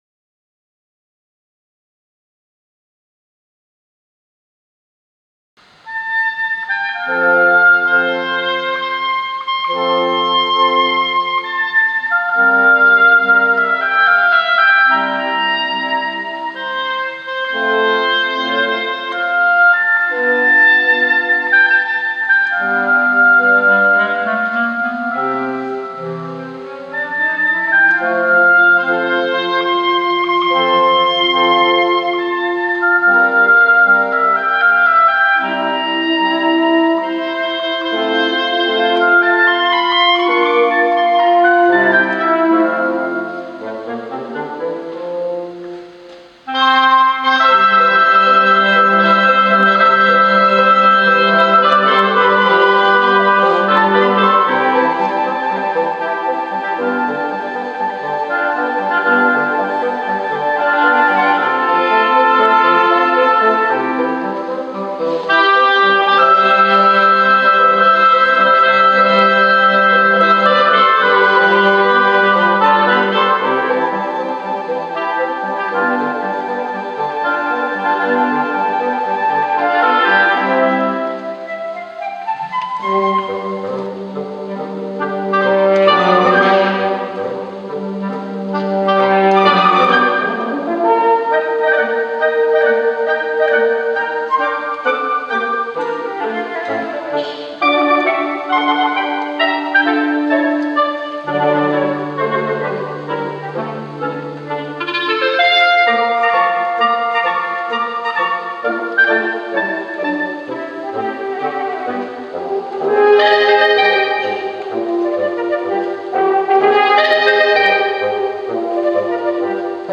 Notre concert du 6 décembre 2015
Le dimanche 6 décembre à 16h00 s'est tenu, à l'église de Pont-de-Vaux, notre concert de Noël.
Nous avons reçu le "Quintette à vents de Lyon", ensemble composé de :
une flûte traversière
une clarinette
un hautbois
un basson
un cor d'harmonie, seul "cuivre" au milieu de ces "bois"
Les artistes nous ont proposé des oeuvres originales et des arrangements pour cette formation.
Le quintette en audio